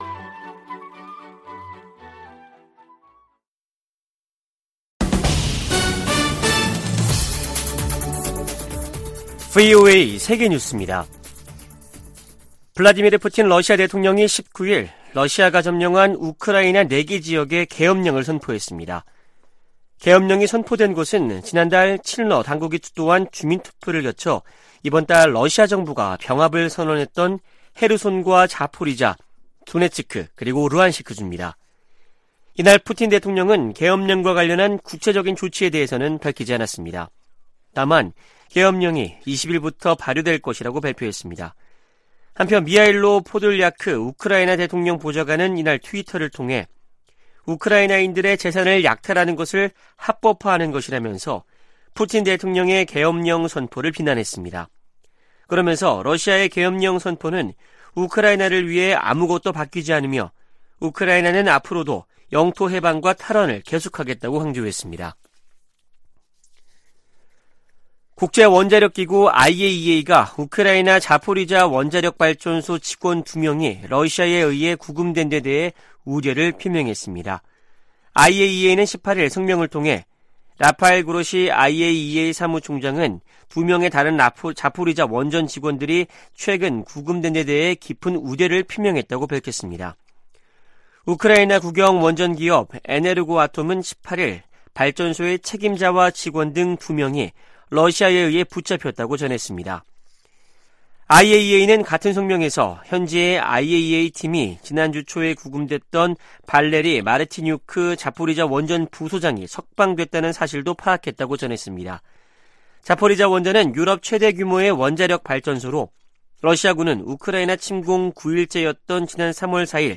VOA 한국어 아침 뉴스 프로그램 '워싱턴 뉴스 광장' 2022년 10월 20일 방송입니다. 북한이 18일 밤부터 19일 오후까지 동해와 서해 완충구역으로 350여 발의 포병 사격을 가하면서 또 다시 9.19 남북군사합의를 위반했습니다. 미 국무부는 북한의 포 사격에 대해 모든 도발적 행동을 중단할 것을 촉구했습니다. 미국 헤리티지재단은 '2023 미국 군사력 지수' 보고서에서 북한의 핵을 가장 큰 군사적 위협 중 하나로 꼽았습니다.